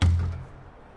描述：用篮球板击球时产生的声音。